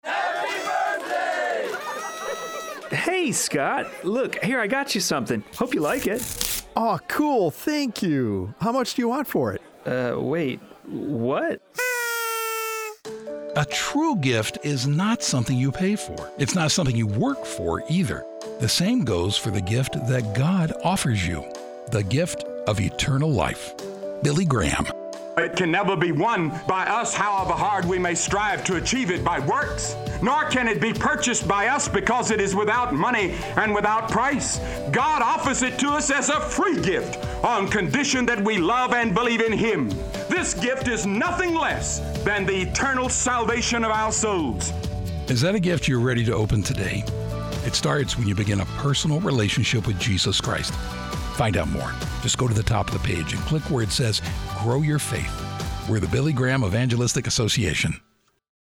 Hear more from Billy Graham in this 1-minute message.